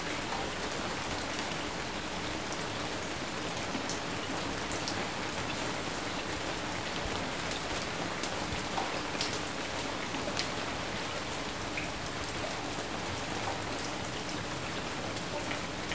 Rain_Light_Loop.ogg